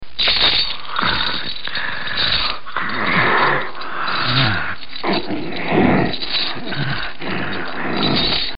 Chains Rattle & Ghoul
Category: Radio   Right: Personal